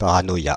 Ääntäminen
Synonyymit philocratie Ääntäminen Paris: IPA: [pa.ʁa.nɔ.ja] France (Île-de-France): IPA: /pa.ʁa.nɔ.ja/ Haettu sana löytyi näillä lähdekielillä: ranska Käännös Substantiivit 1. параноя {f} (paranoja) Suku: f .